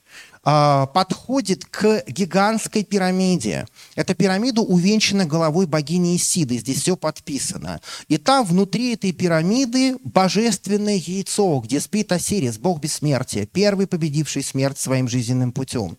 Пример того, что выходит в итоге.